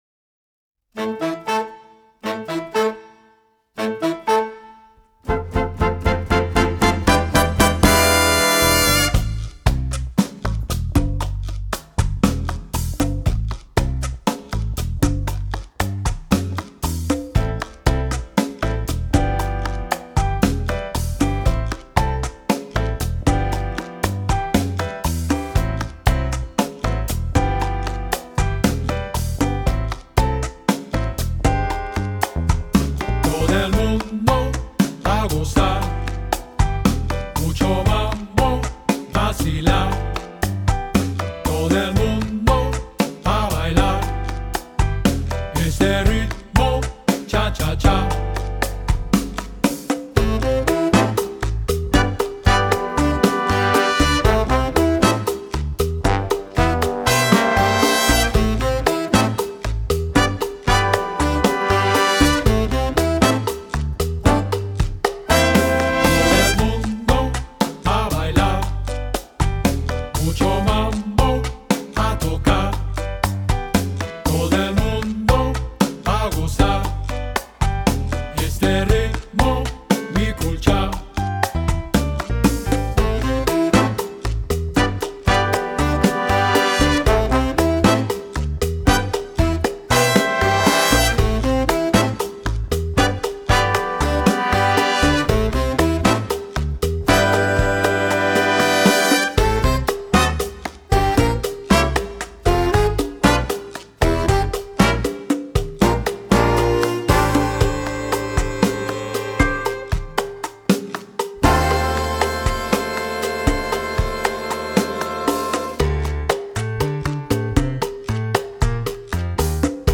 mixing in elements of funk and jazz to create a unique edge.